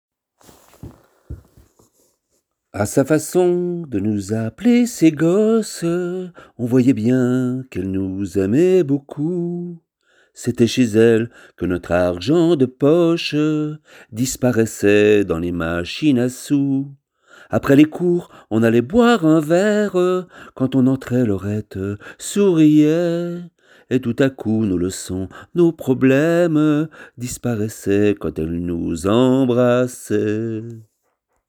40 - 65 ans - Ténor